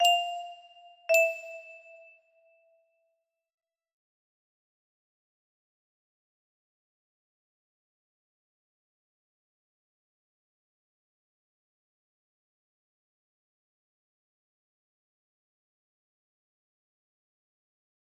Unknown Artist - Untitledhhh music box melody